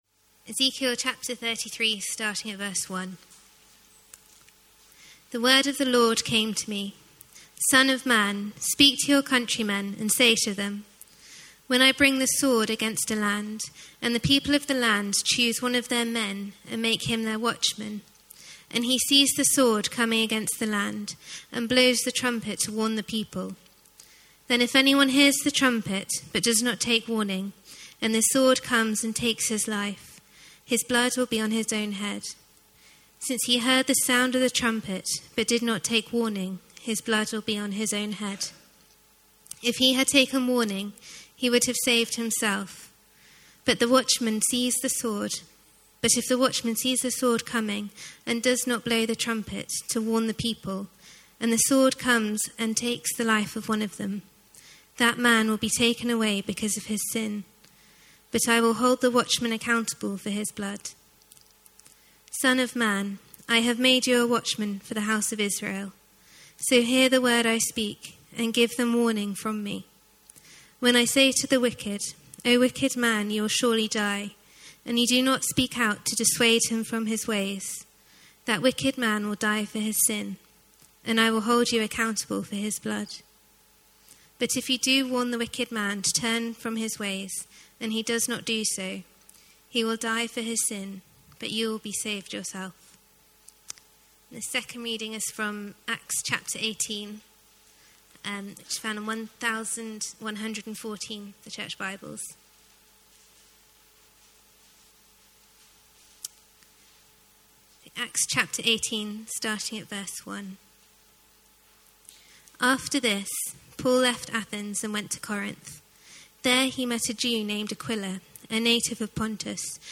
A sermon preached at Dundonald Church, London UK